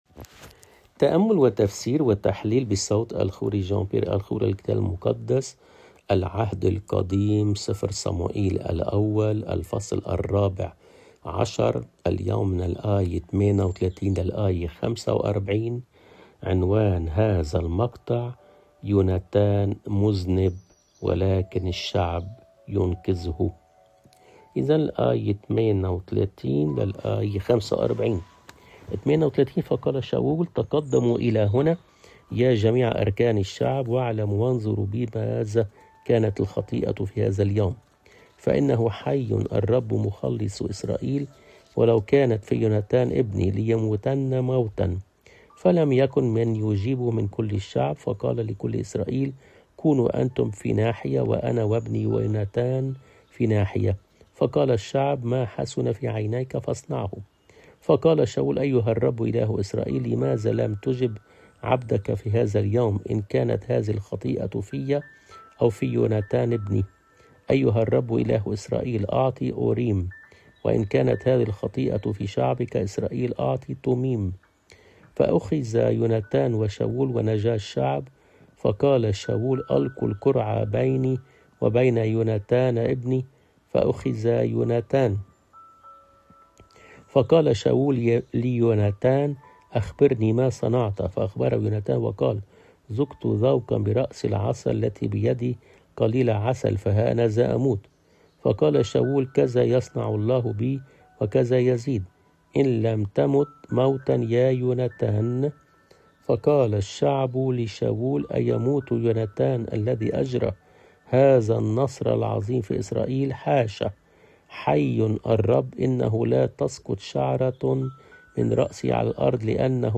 بصوت